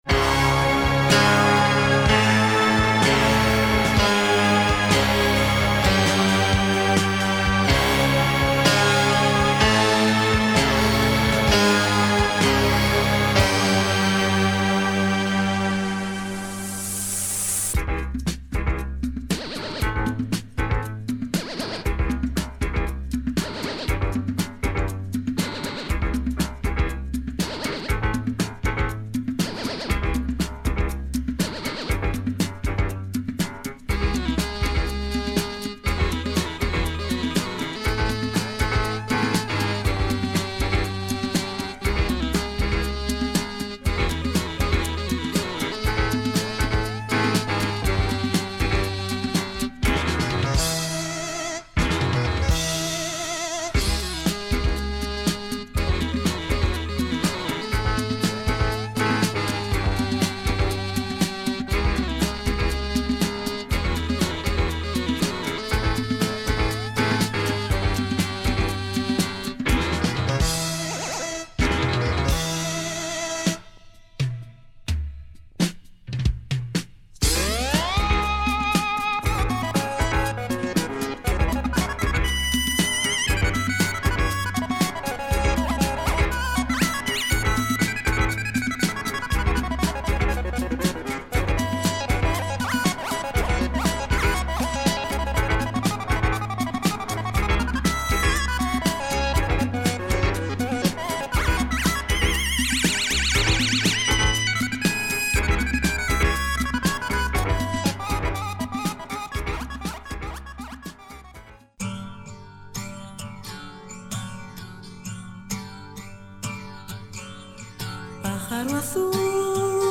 In demand Spanish prog album
the devastating prog funk